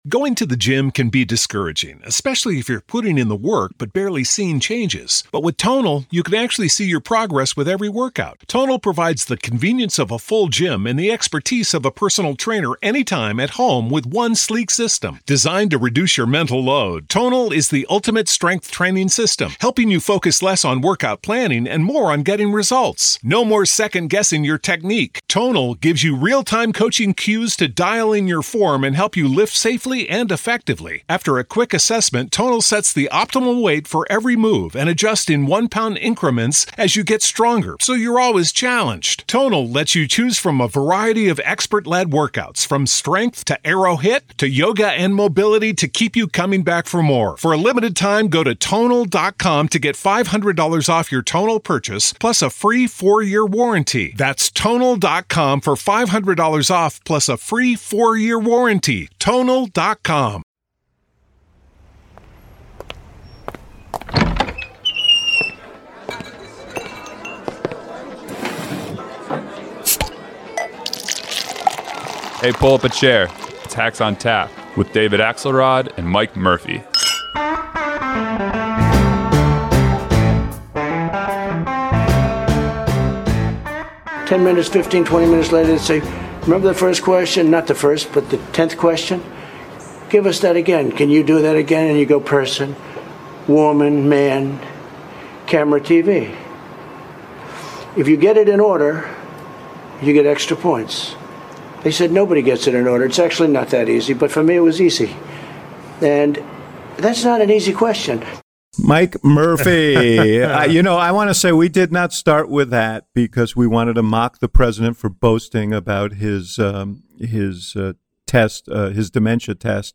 This week we’re joined by super Hack John Heilemann, co-host of The Circus on Showtime.
And with time running out for Biden to announce his VP pick, will he make a head or a heart decision? Plus, the recent polls are good news for Biden, but are Democrats too complacent? The Hacks discuss all this and more!